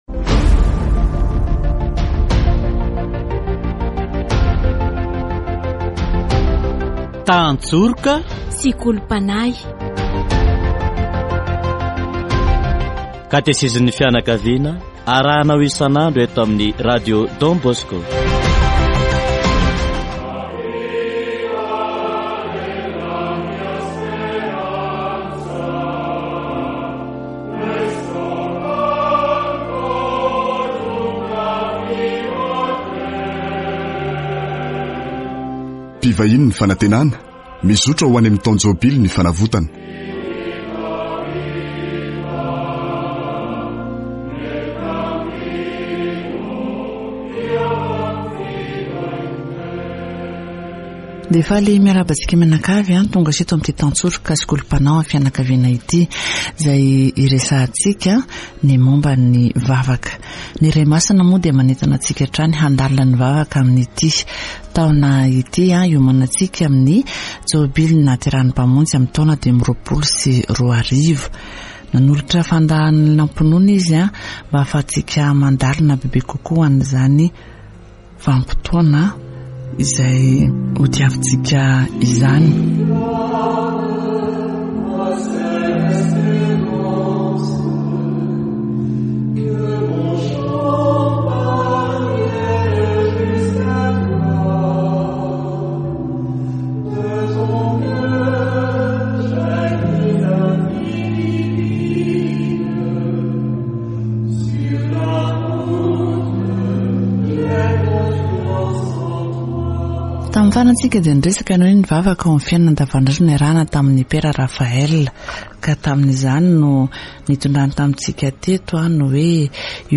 Catechesis on persevering prayer